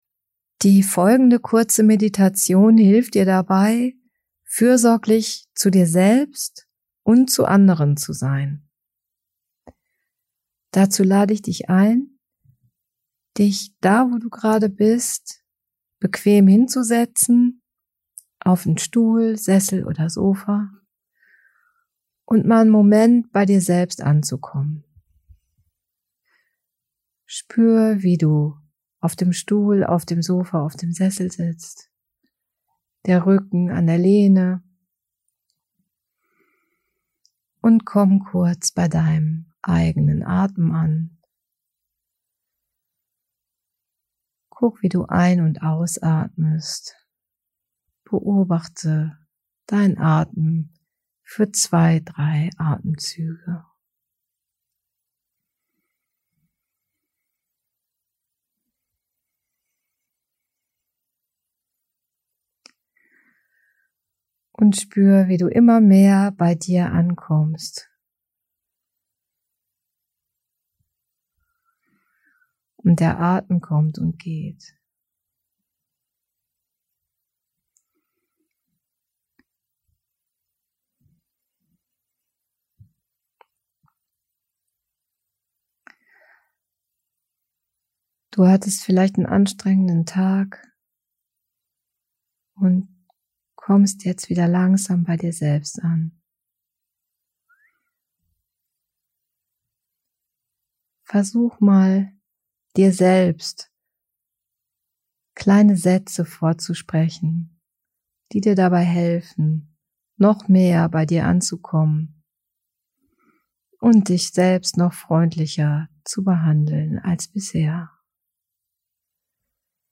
Woche 6 Teil 2: Metta-Meditation - Freundliche Wünsche
Woche6.2_Metta-Meditation_NEU.mp3